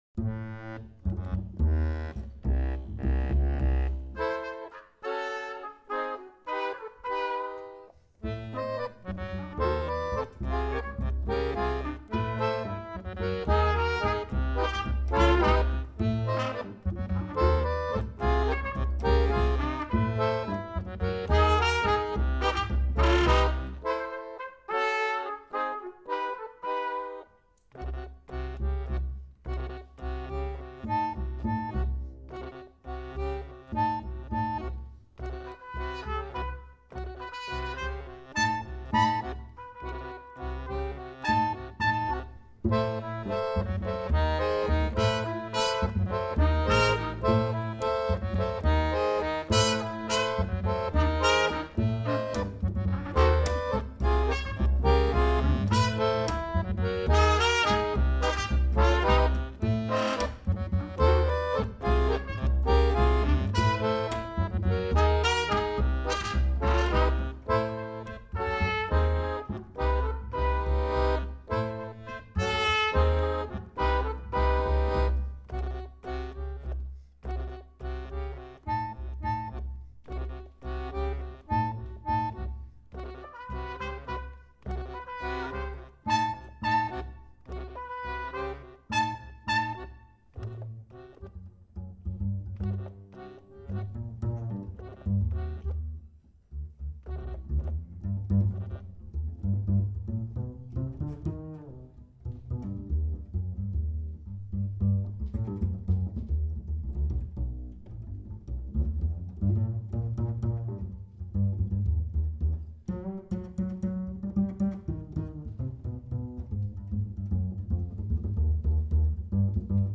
the accordion.